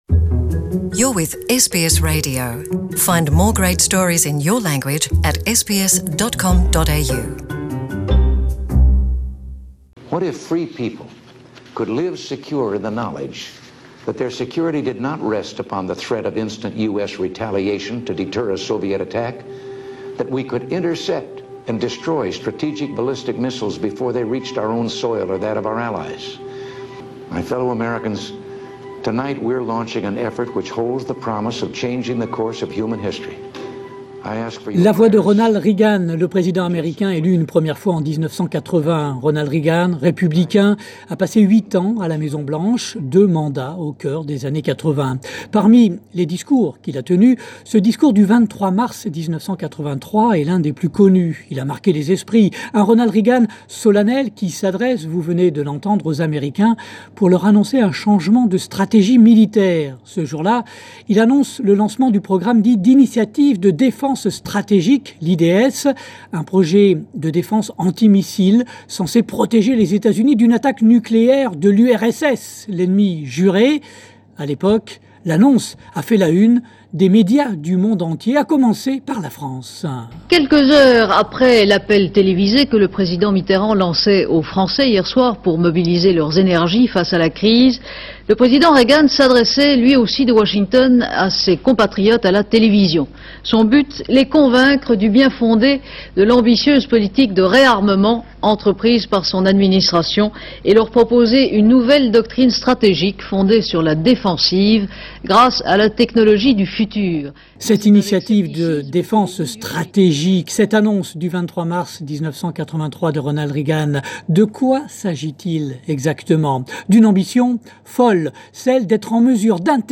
Le 23 mars 1983, le président américain Ronald Reagan lançait ce qu'on a appelé alors la guerre des étoiles. Retour sur cet épisode avec les archives sonores de l'Institut National de l'Audiovisuel.